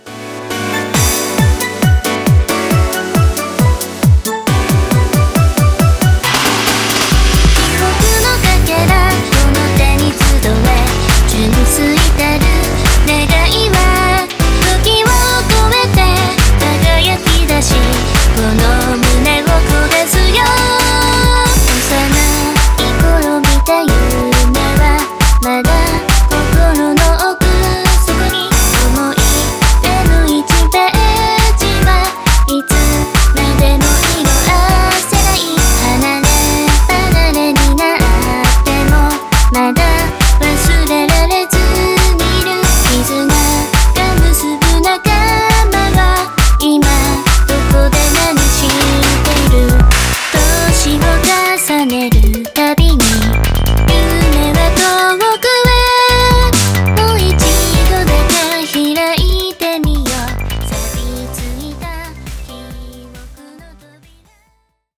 HOUSE J-POP